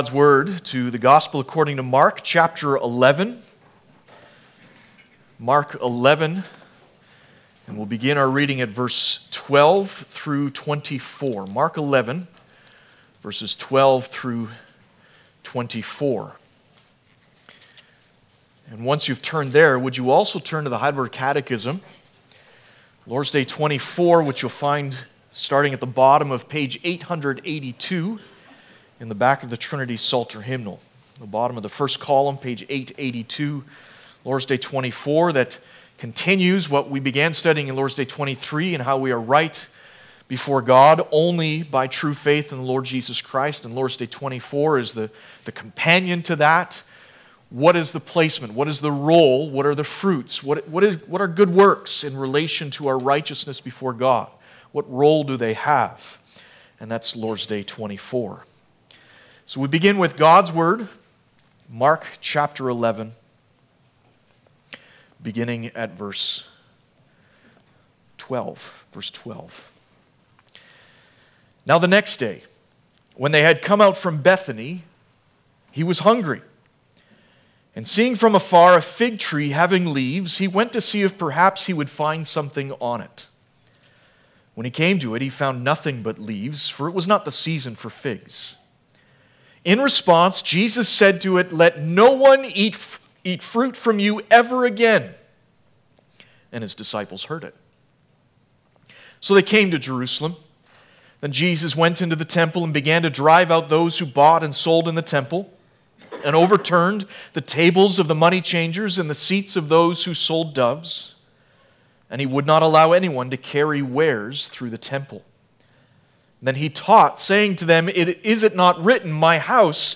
Passage: Mark 11:12-24 Service Type: Sunday Morning